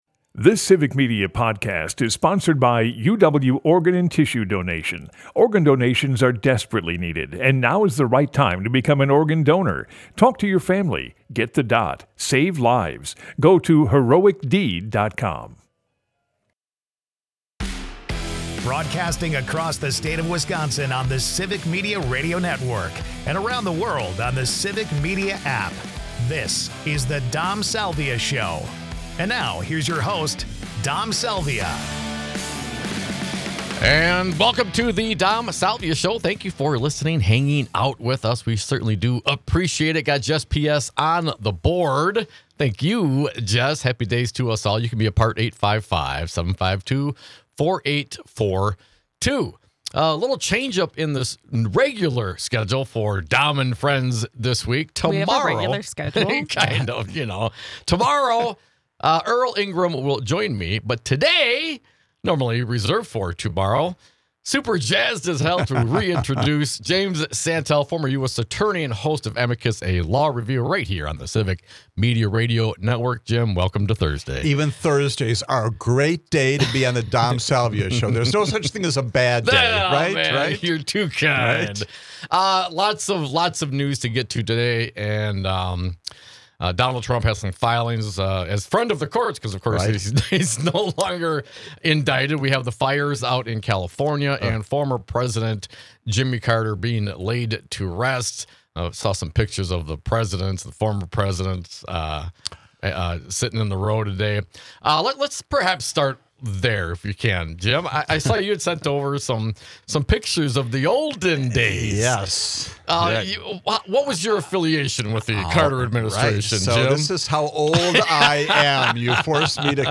Jim Santelle 1/9/2025 Listen Share Jim Santelle, former US Attorney and host of Civic Media's Saturday show Amicus: A Law Review joins us as cohost.